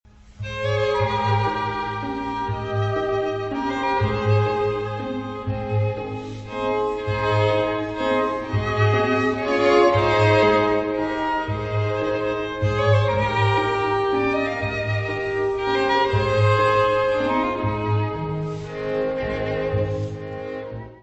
violino
contrabaixo
Área:  Música Clássica